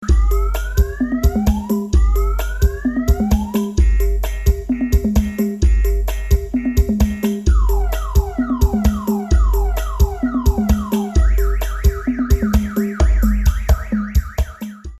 Genre: Nada dering remix